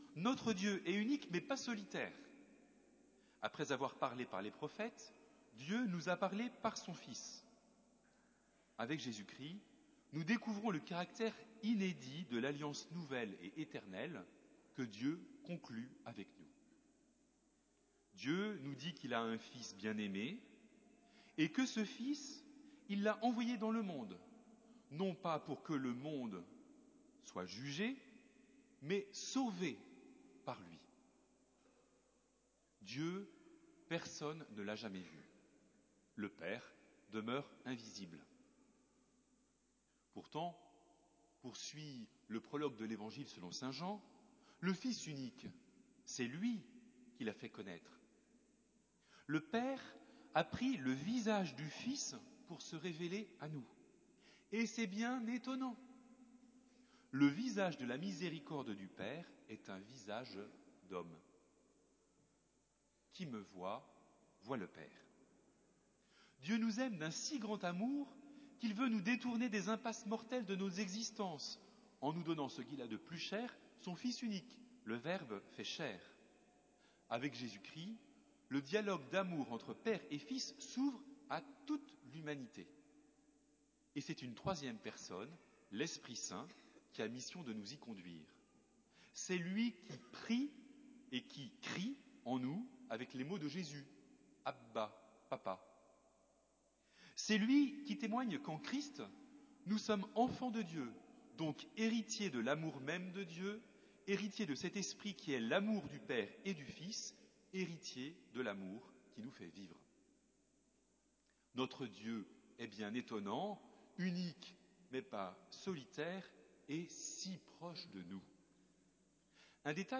homélies